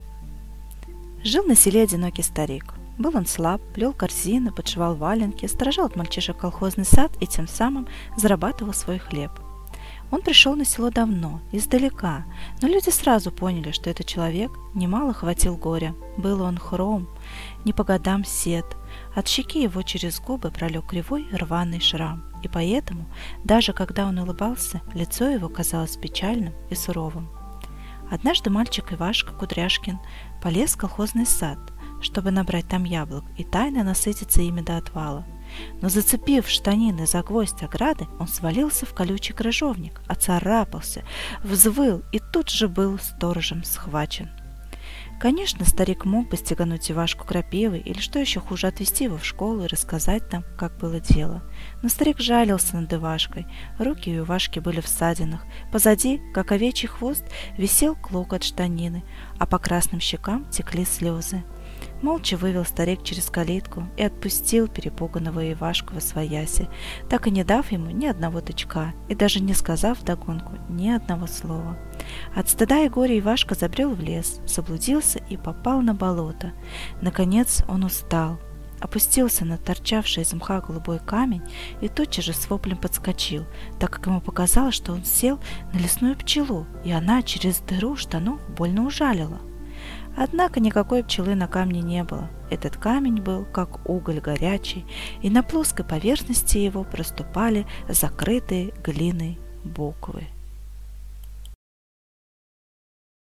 Январский подкаст студии звукозаписи Наумовской библиотеки приурочен к 120-летию со дня рождения детского писателя Аркадия Петровича Гайдара.
А потом вместе записали аудиотреки отрывков из произведений «Чук и Гек», «Голубая чашка», «Горячий камень» и, конечно, «Тимур и его команда».
Rasskaz-Goryachij-kamen-Arkadij-Gajdar.mp3